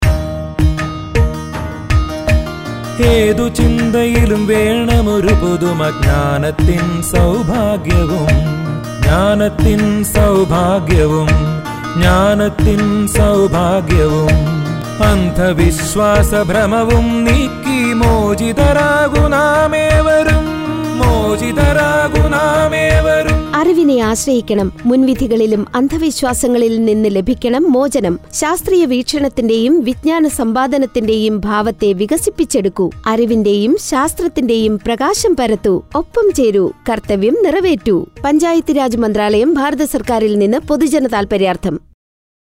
131 Fundamental Duty 8th Fundamental Duty Develop scientific temper Radio Jingle Malayamlam